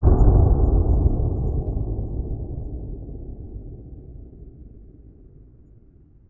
地下环境 " 沉重的下降长
描述：SFX。沉重的坠落声和长长的回声。也可能听起来像深度爆炸。
标签： 重下降 呼应 爆炸
声道立体声